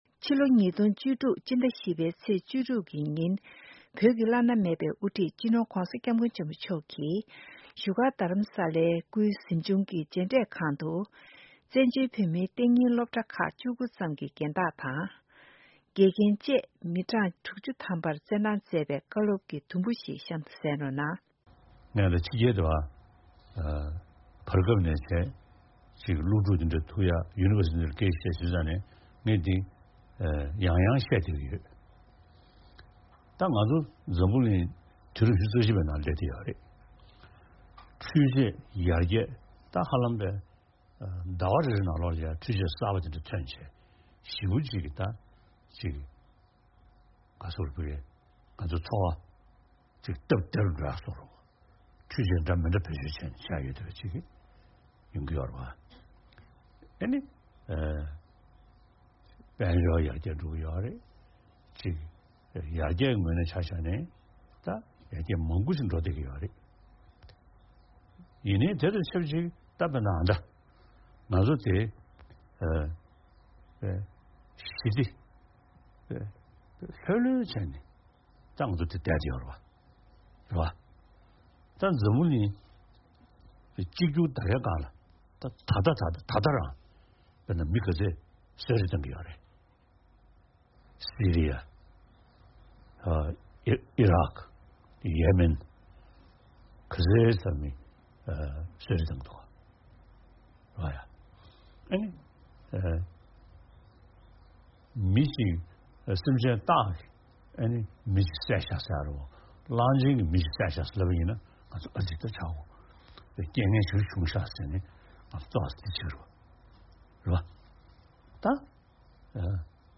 སྤྱི་ནོར་༧གོང་ས་༧སྐྱབས་མགོན་ཆེན་པོ་མཆོག་གིས་ཕྱི་ལོ་༢༠༡༦ ཕྱི་ཟླ་༤པའི་ཚེས་༡༦གི་ཉིན་བོད་མིའི་གཏན་ཉིན་སློབ་གྲྭ་ཁག་གི་རྒན་བདག་དང་ དགེ་རྒན་ཁག་ཅིག་ལ་བསྩལ་གནང་མཛད་པའི་བཀའ་སློབ་ཀྱི་དུམ་བུ་དང་པོ་དེ་གསན་རོགས་གནང་།